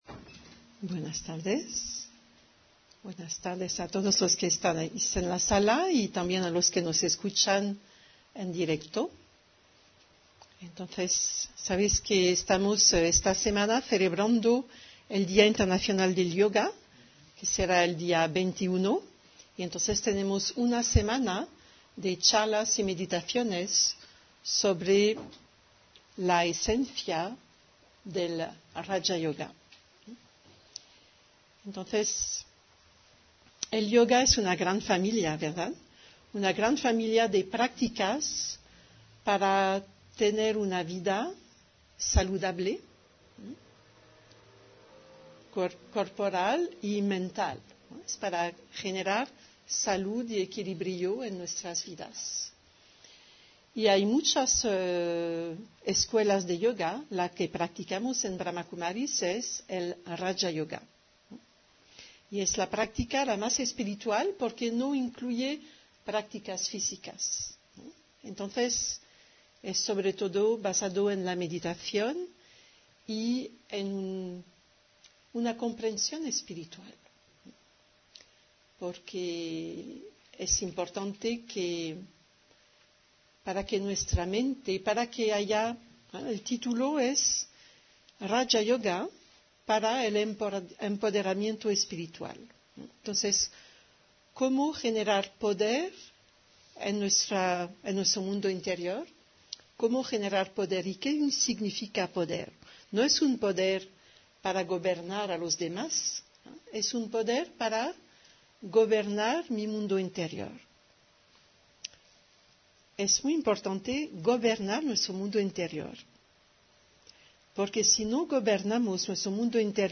Meditación y conferencia: Determinación, paciencia y constancia (10 Septiembre 2025)